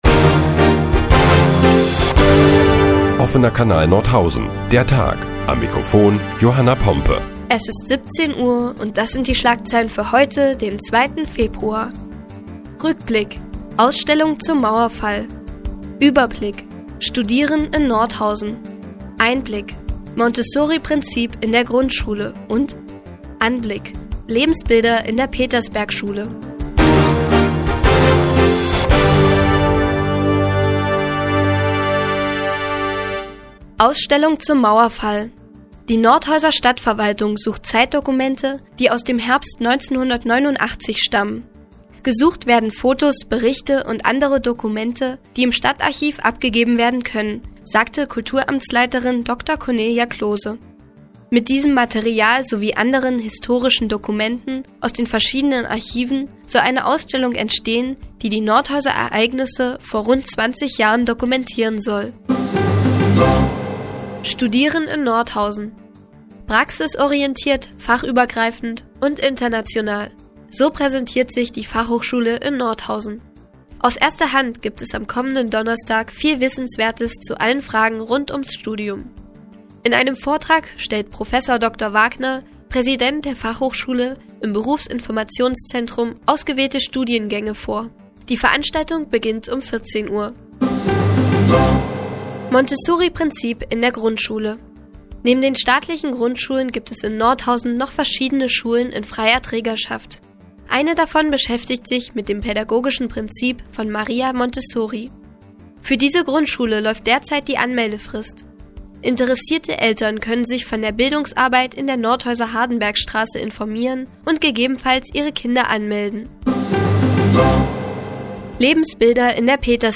Die tägliche Nachrichtensendung des OKN ist nun auch in der nnz zu hören. Heute geht es unter anderem um eine Ausstellung zum Mauerfall und das Montessori-Prinzip in Grundschulen.